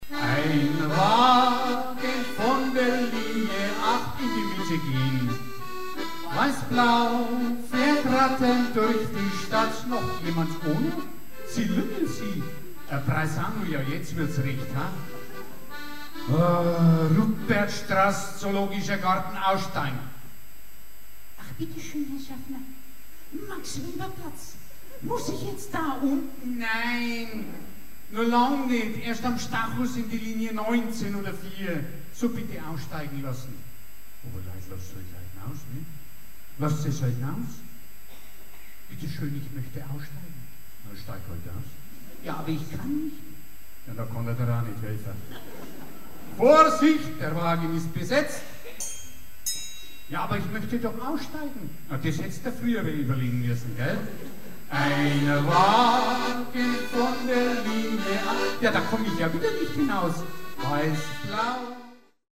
Konzert 2006 -Download-Bereich
-------Das Orchester-------